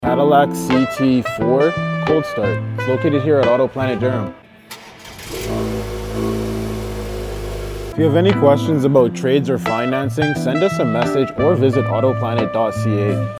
Cadillac CT4 Cold Start sound effects free download